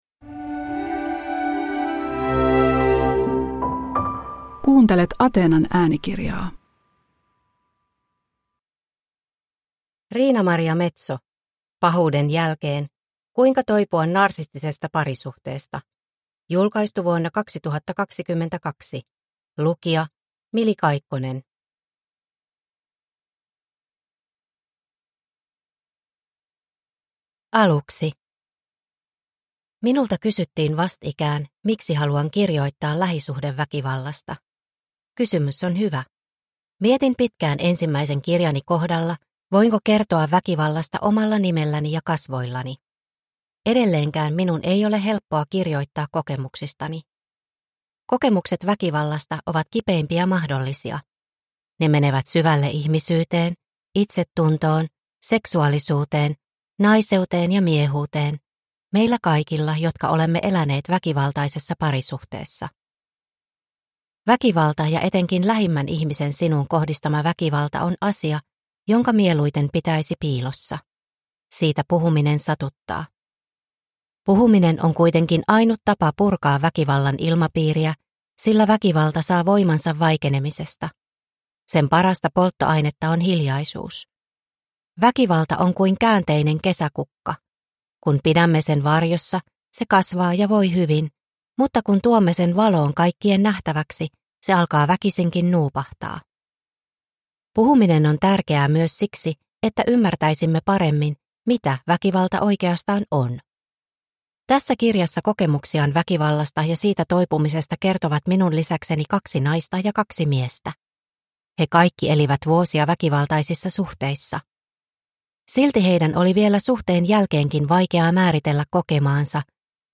Pahuuden jälkeen – Ljudbok – Laddas ner